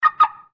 Звуки брелка сигнализации: Как снять автомобиль с охраны с помощью брелка (двойной пик)